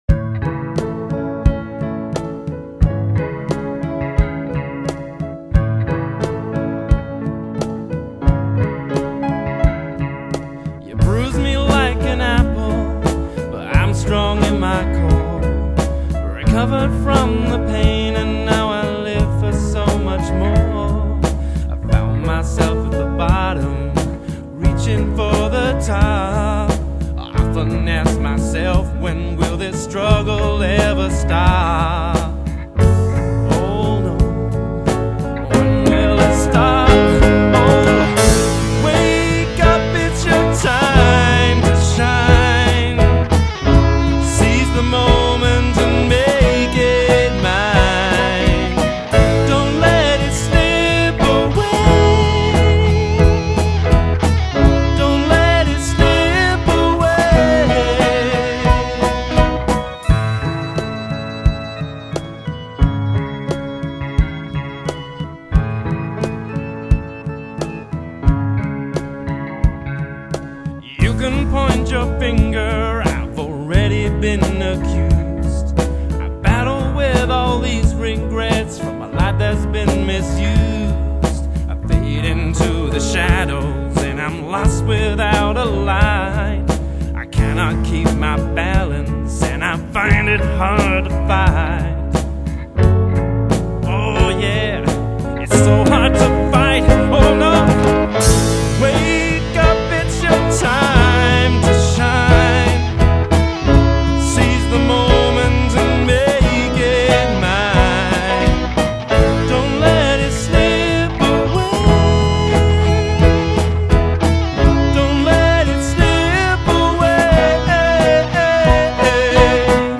We had a week to Write, Arrange and record an original song in a 16 track digital studio in Kent.
I wrote the lyrics and sung the vocals, over the group of Guitar, Bass, Keyboard & Drums.